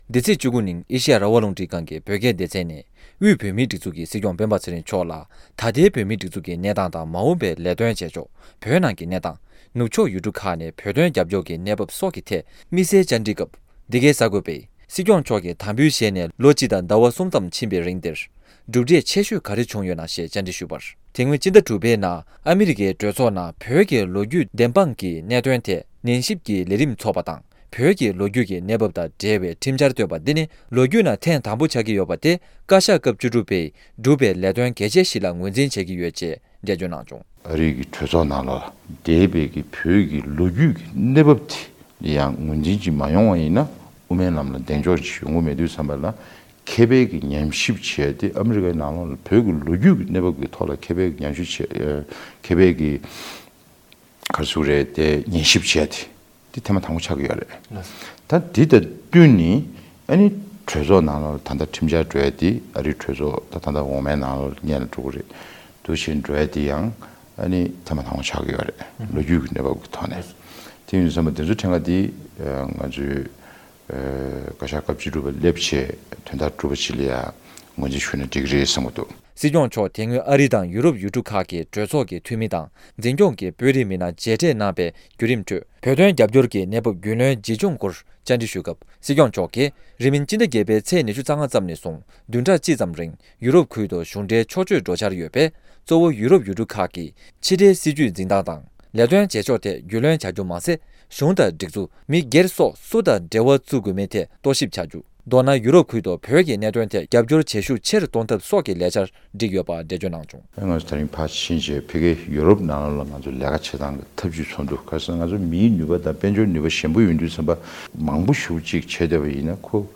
འདི་ཚེས་༡༩ ཉིན་ཨེ་ཤེ་ཡ་རང་དབང་རླུང་འཕྲིན་ཁང་གི་བོད་སྐད་སྡེ་ཚན་ནས་དབུས་བོད་མིའི་སྒྲིག་འཛུགས་ཀྱི་སྲིད་སྐྱོང་སྤེན་པ་ཚེ་རིང་མཆོག་དང་ལྷན་དུ་དམིགས་བསལ་བཅར་འདྲི་སྐབས།
སྒྲ་ལྡན་གསར་འགྱུར།